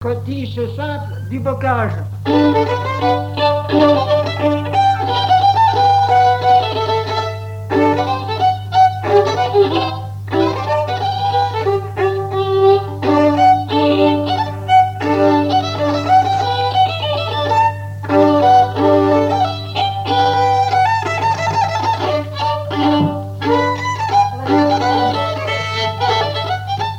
danse : scottich trois pas
Répertoire du violoneux
Pièce musicale inédite